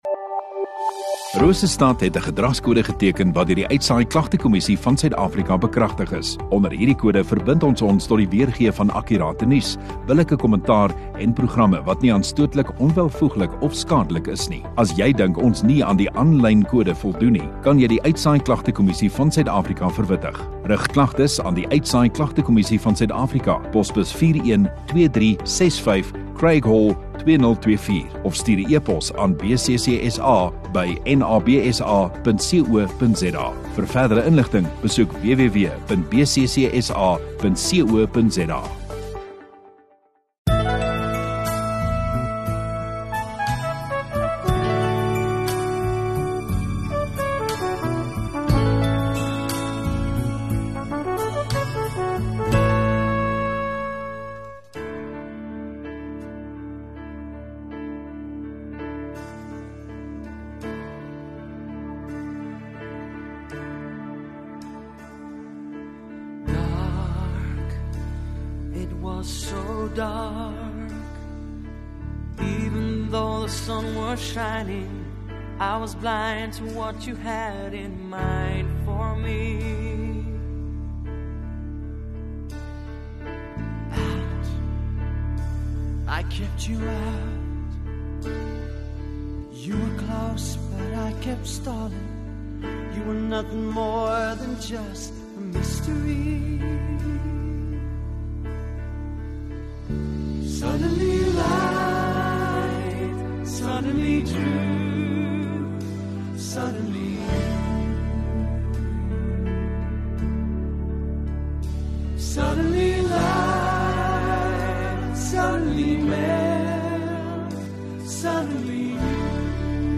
18 Aug Sondagoggend Erediens